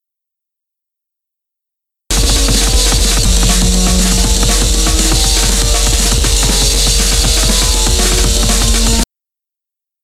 The first is the original 'music', actually just some vaguely musical noises produced by a computer synthesiser, designed to have a wide range of frequency components.
ORIGINAL TEST SIGNAL.